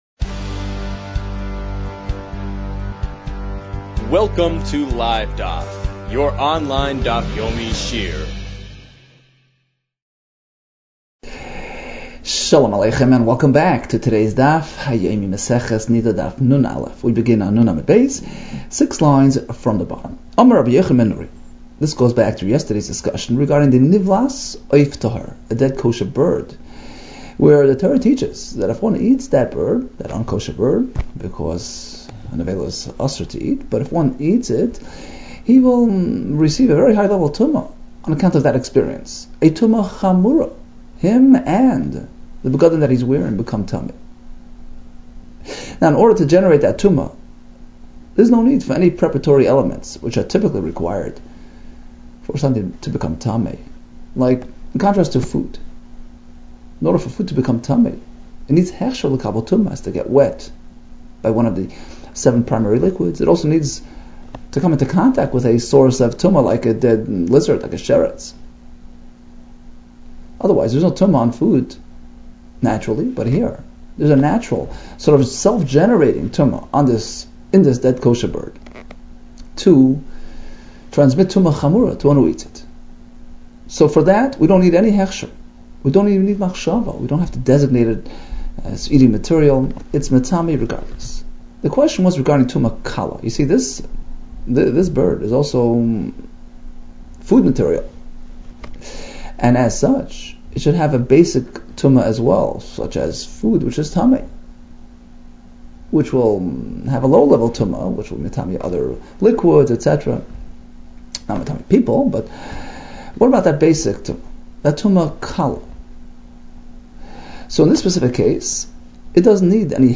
Niddah 50 - נדה נ | Daf Yomi Online Shiur | Livedaf